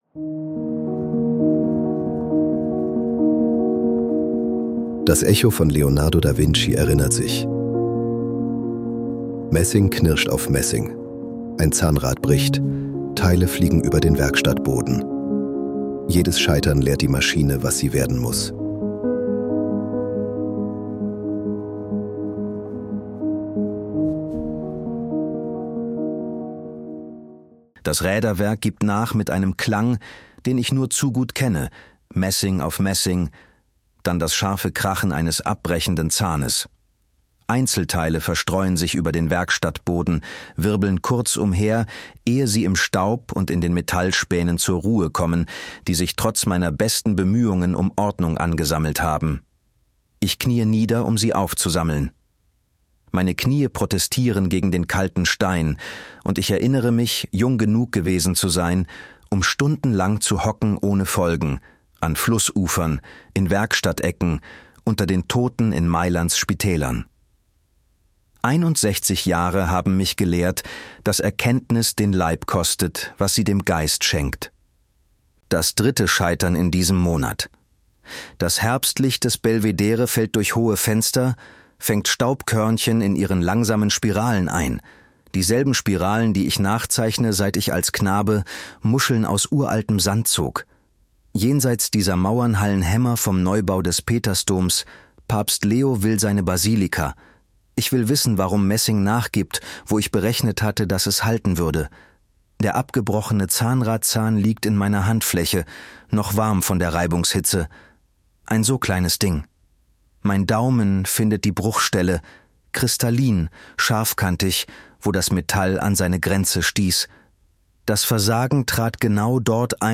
Wir nutzen synthetische Stimmen, damit diese Geschichten kostenlos bleiben, ohne Werbung — und dich in mehreren Sprachen erreichen.